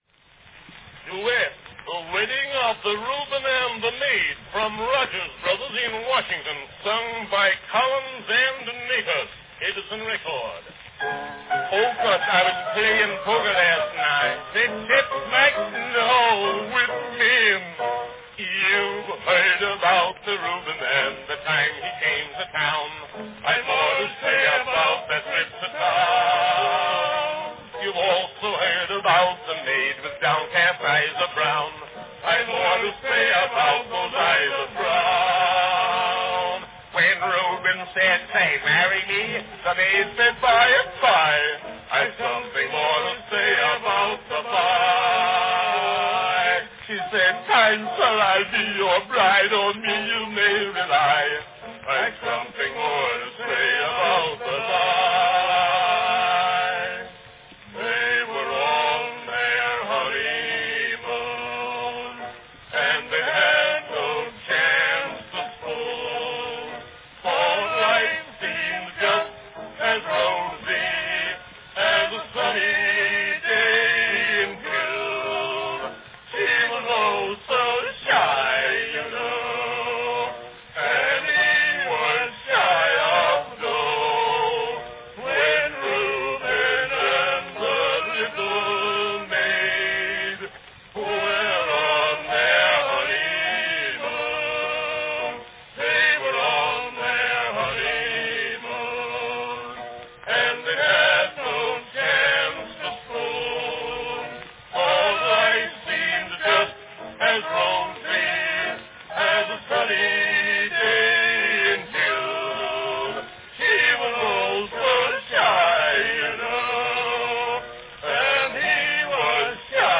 A popular vaudeville comic-pun song from 1901, The Wedding of the Reuben and the Maid, sung by Arthur Collins and Joseph Natus.
Cylinder # 7969 (5-inch "Concert" cylinder)
Category Duet
Performed by Arthur Collins & Joseph Natus
Announcement "Duet, The Wedding of the Reuben and the Maid, from Rogers Brothers in Washington, sung by Collins and Natus.   Edison record."
Here, Arthur Collins and Joseph Natus perform - on a 5-inch diameter Edison "concert" wax cylinder - a popular number from the Rogers brothers' 1901 production.